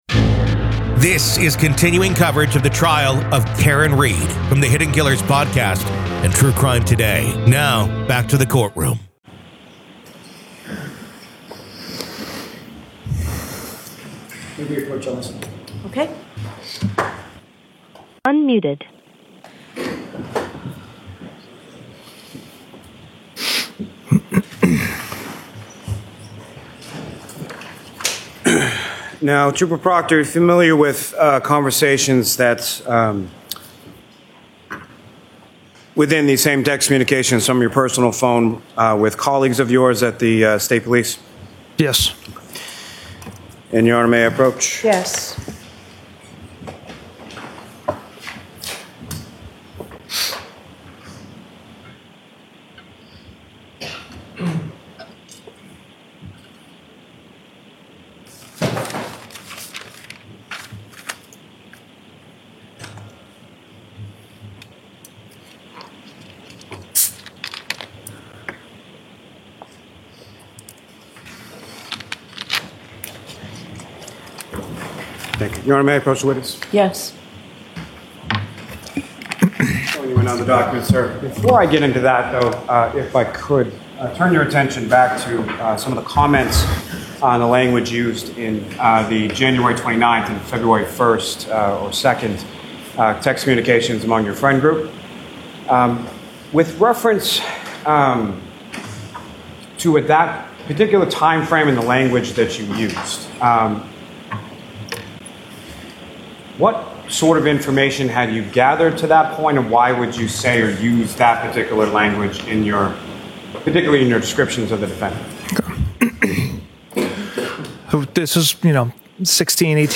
SOME LANGUAGE MAY BE OFFENSIVE: